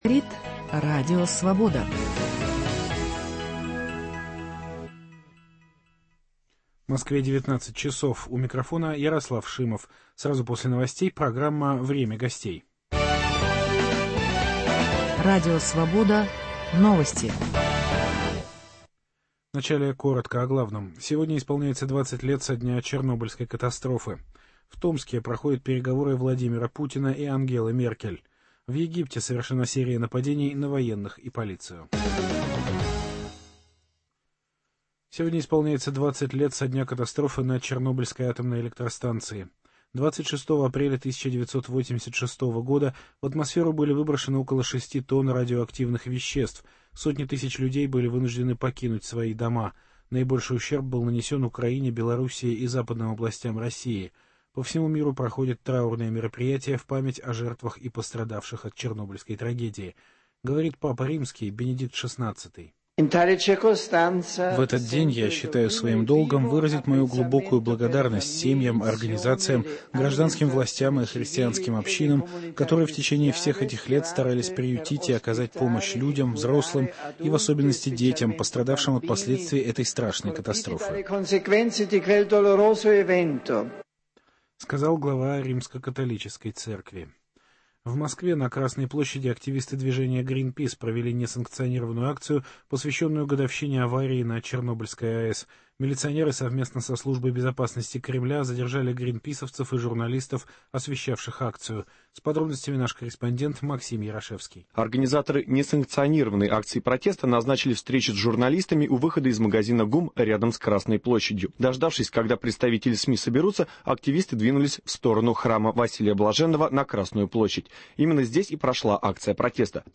В двадцатую годовщину чернобыльской катастрофы в студию программы «Время гостей» приглашен Анатолий Громыко, доктор исторических наук, профессор, член-корреспондент Академии наук, президент Общественного движения за новый мировой демократический правопорядок и в поддержку Организации Объединенных Наций.